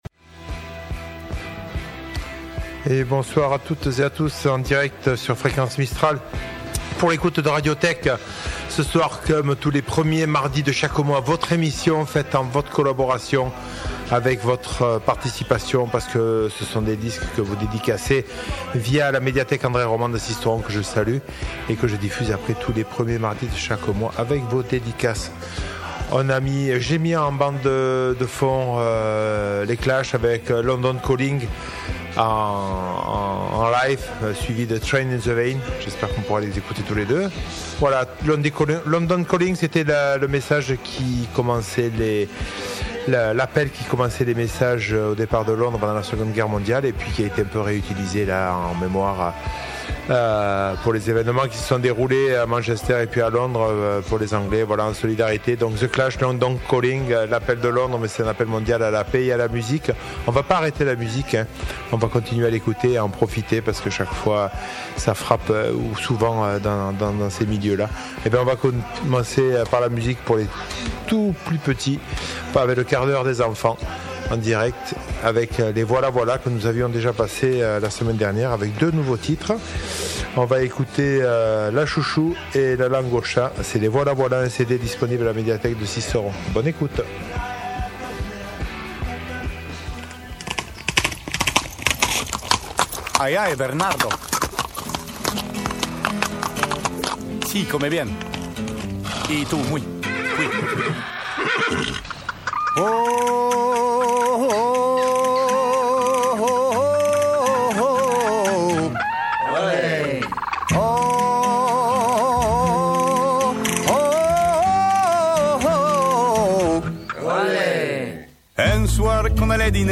Le tout agrémenté par de la chanson pour enfants en début de soirée, du jazz, du blues, de la bonne chanson française et du classique pour terminer l'émission en beauté.